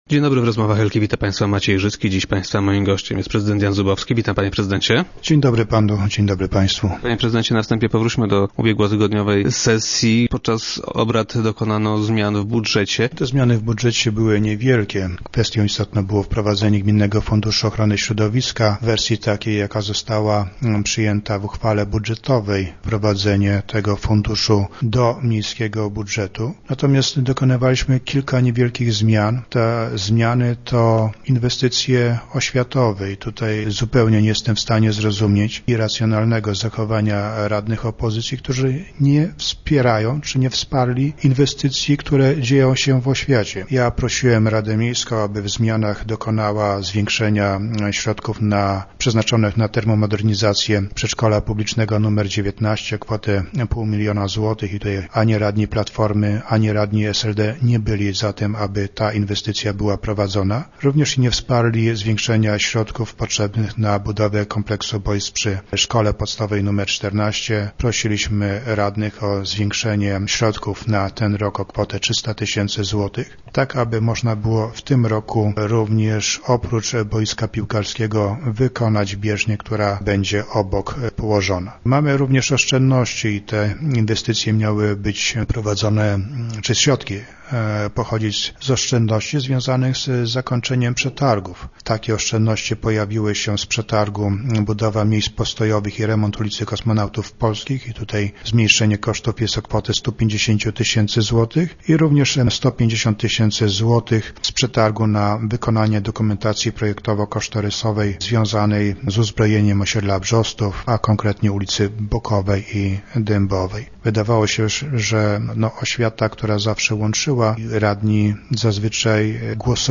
- Nie rozumiem ich zachowania - powiedział prezydent Jan Zubowski, gość dzisiejszych Rozmów Elki.